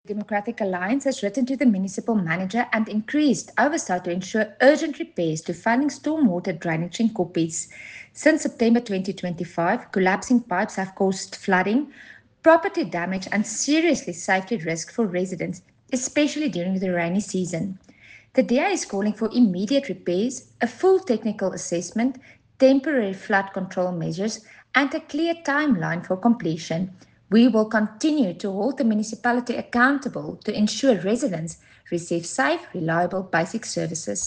Afrikaans soundbites by Cllr Carina Serfontein and